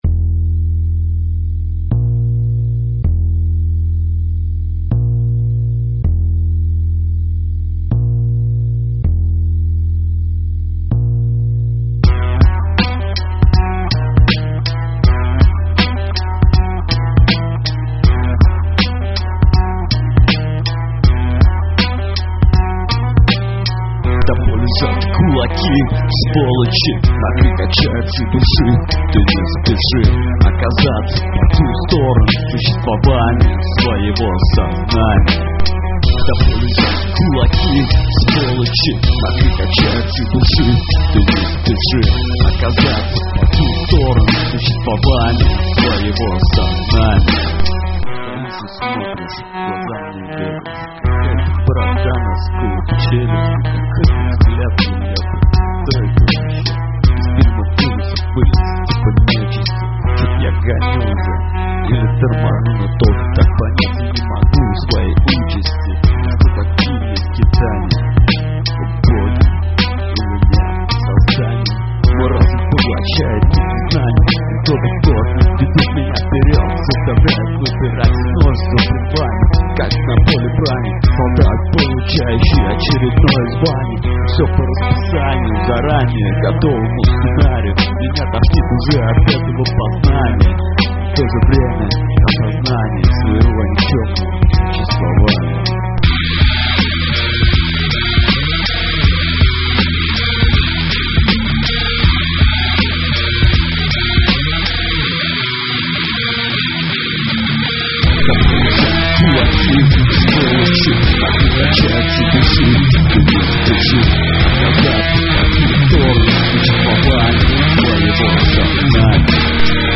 З.Ю.:Вот мой трек записаный кустарным образом (Вокал и гитара - это мя))))) )
З.З.Ю.: очень плохое качество 32Кбит, но впринципе рисунок песни слышен, хотя может мне так кажется? 0.о ))))